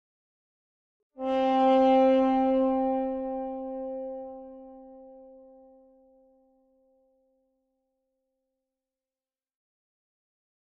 Tuba Tone 1 - Single, Lower